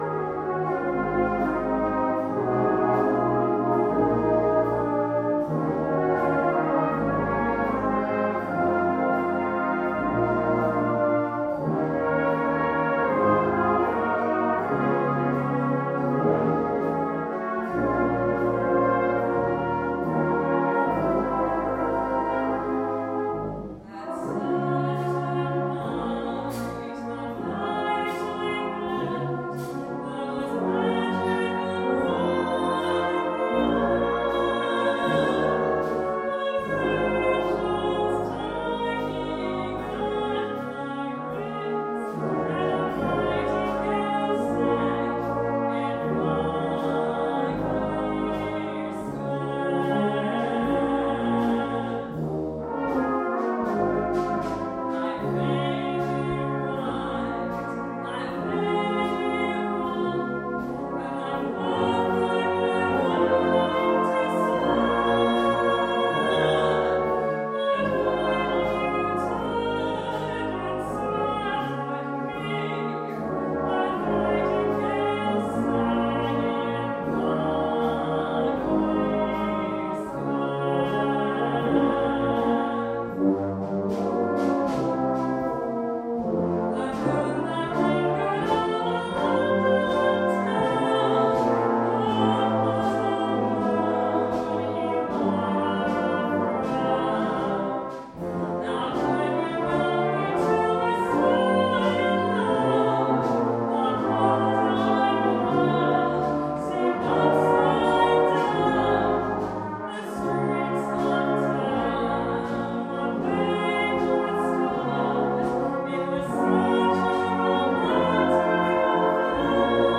The following pieces were recorded at our concert at Foxearth Church on 19th September 2015
sings the wartime song with band accompaniment